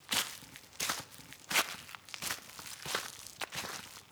SFX_Footsteps_Leaves_01.wav